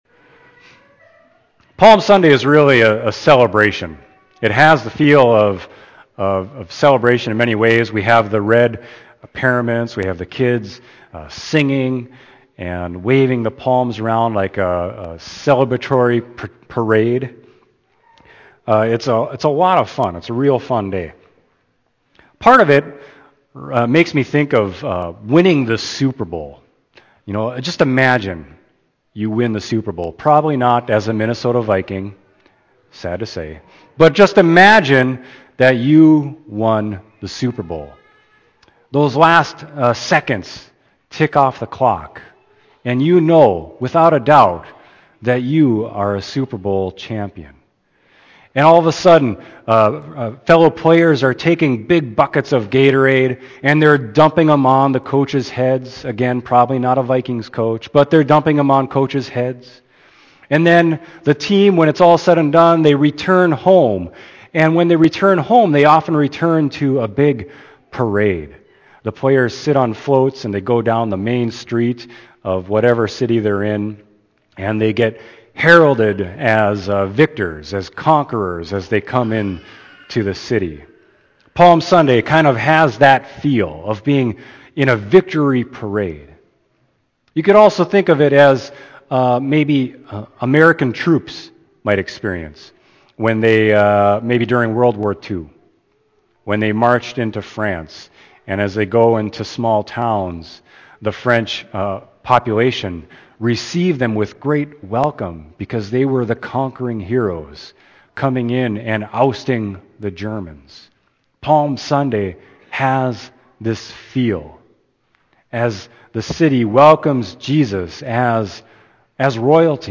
Sermon: Mark 14.1-11